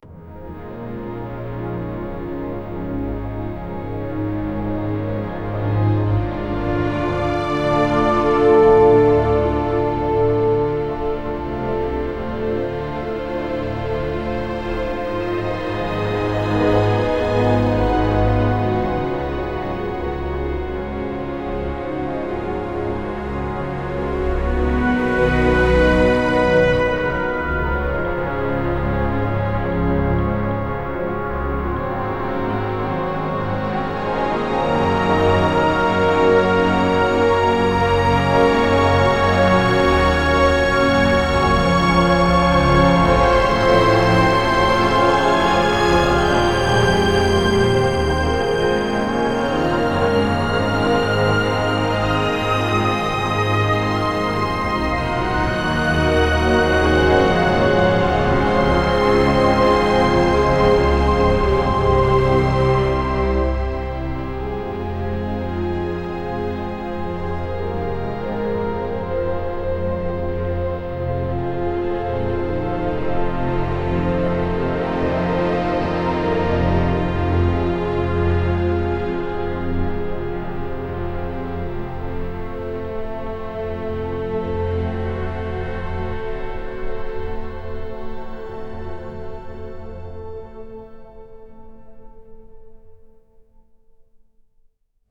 music
ChoirChordsBassMaster_3.wav